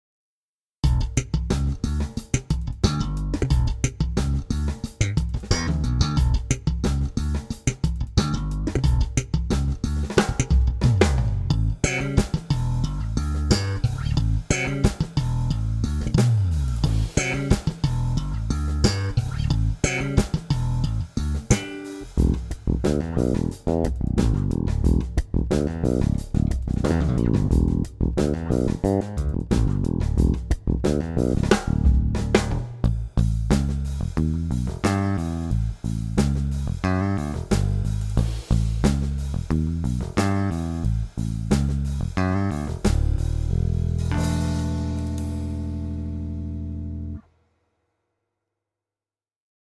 These were all made using ManyBass sounds: there has been no external processing.
ManyBass - Various Grooves